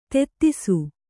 ♪ tettisu